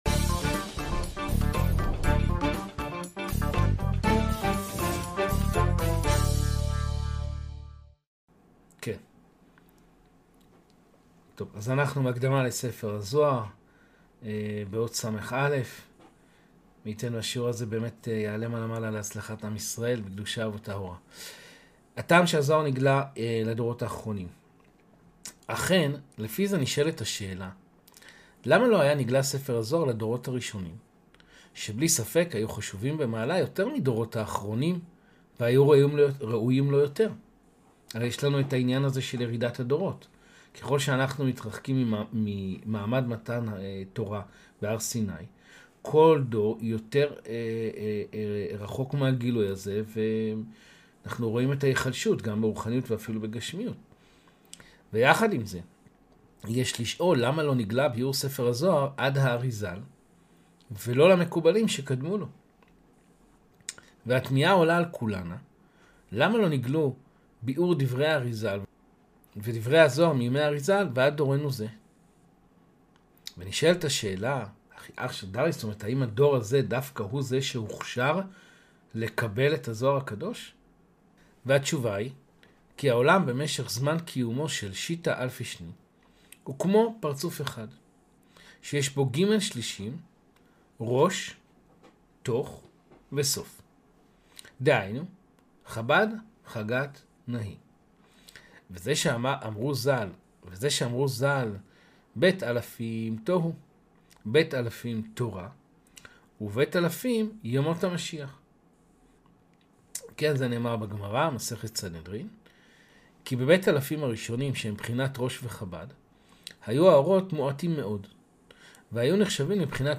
הקדמה לספר הזוהר 29 | שידור חי מהזום
לימוד קבלה בתורות בעל הסולם